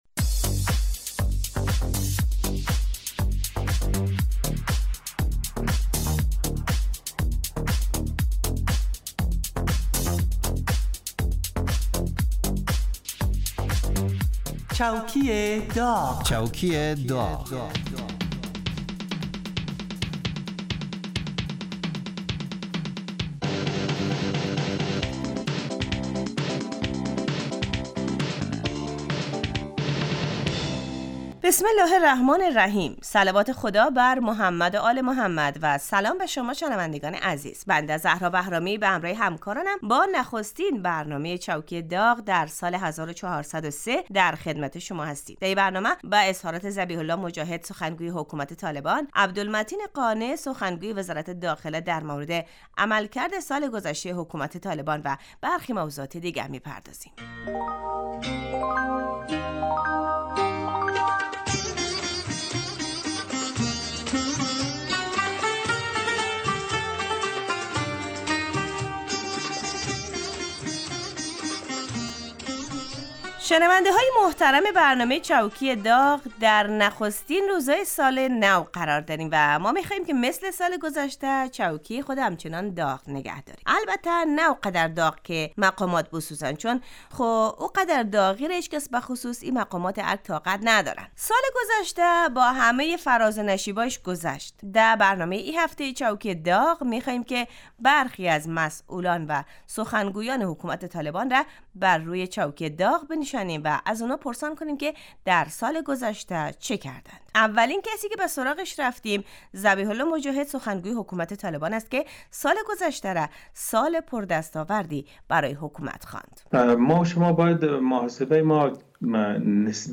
برنامه چوکی داغ به مدت 35 دقیقه روز جمعه در ساعت 14:15 (به وقت افغانستان) پخش می شود. این برنامه با نیم نگاه طنز به مرور و بررسی اخبار و رویدادهای مهم مربوط به دولت حاکم در افغانستان می پردازد.